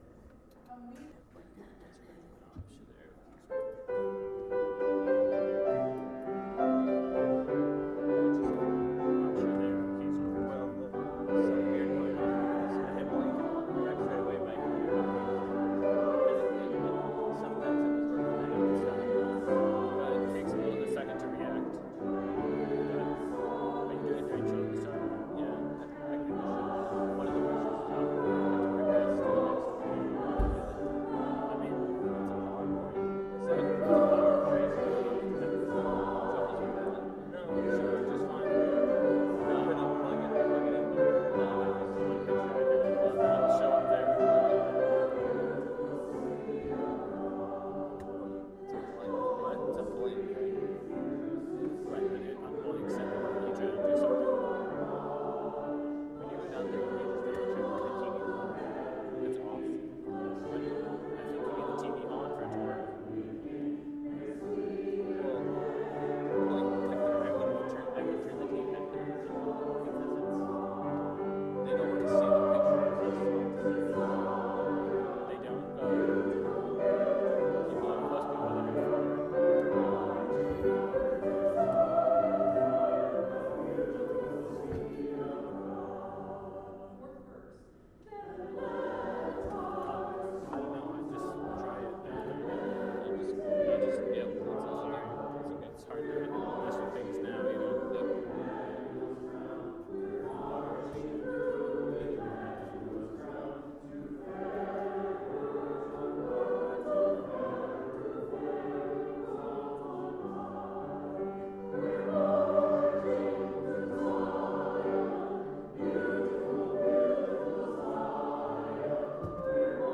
Bible Text: Romans 6:5-12 | Preacher